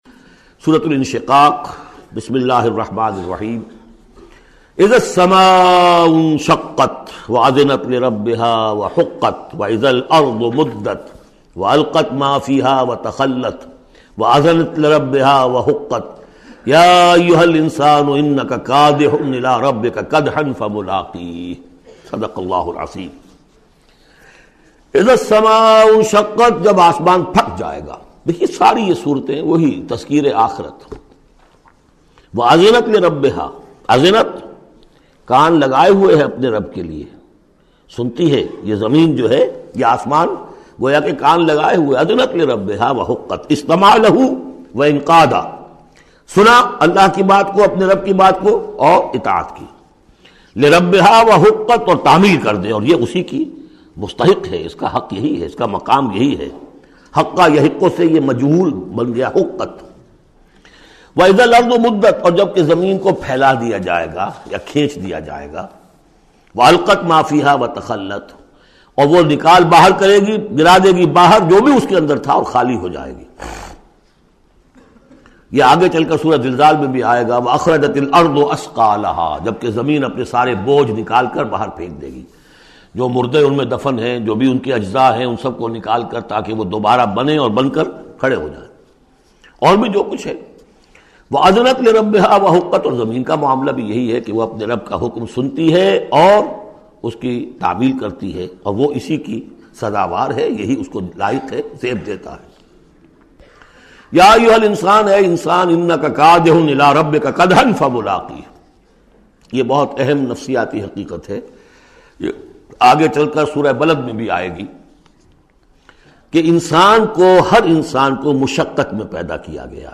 Surah Inshiqaq Audio Tafseer by Dr Israr Ahmed